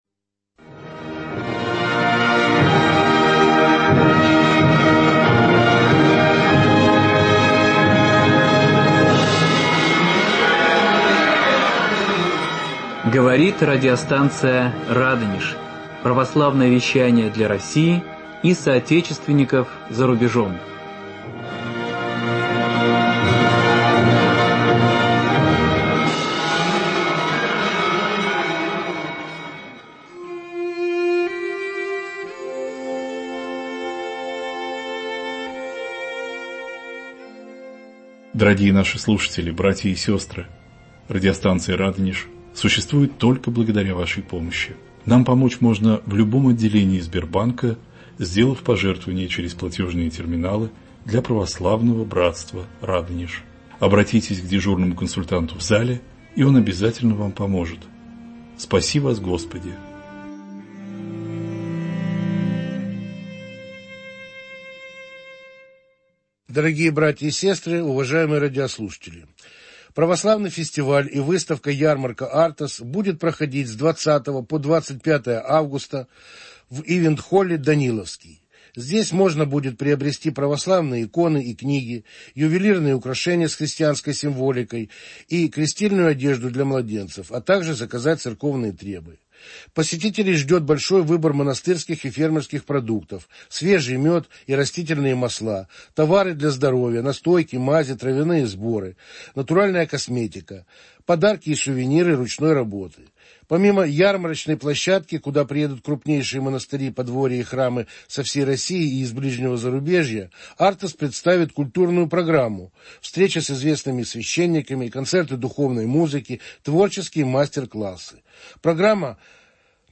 Беседа 3